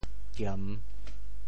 潮州 giem3 hê5 潮阳 giam3 hê5 潮州 0 1 潮阳 0 1